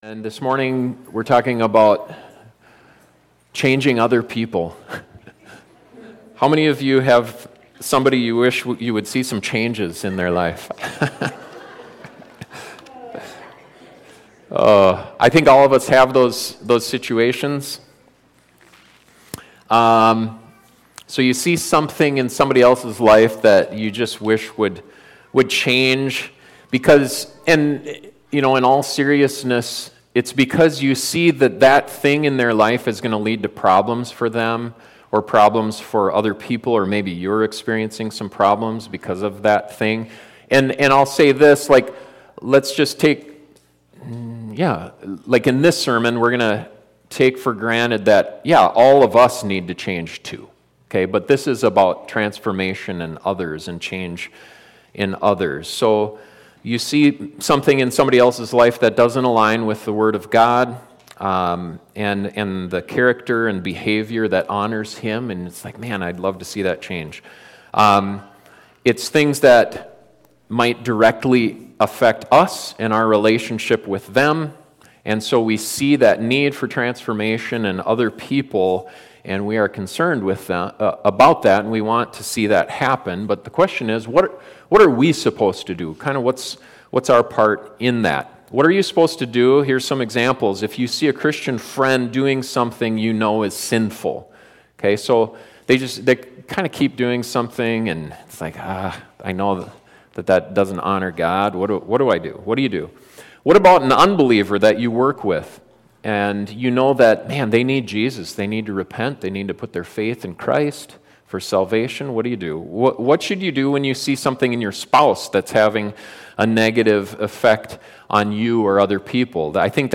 We all have situations like that and this sermon looks at a biblical approach to seeking transformation in others.